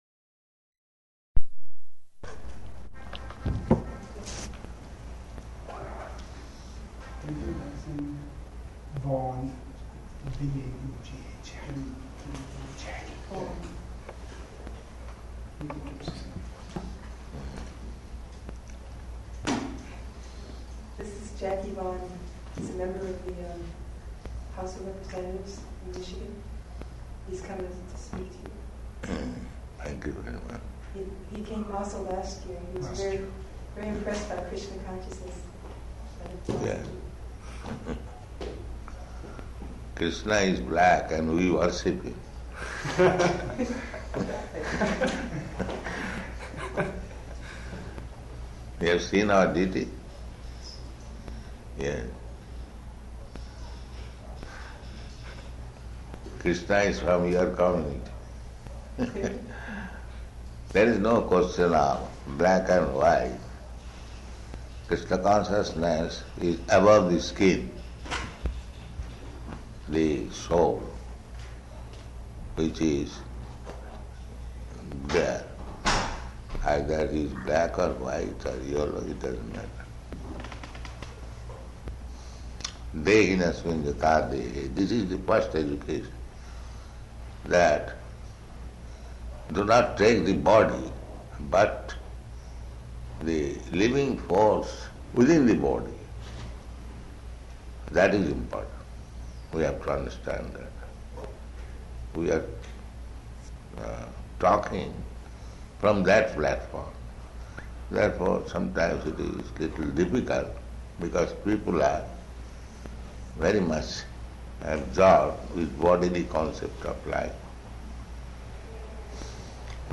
Type: Interview
Location: Detroit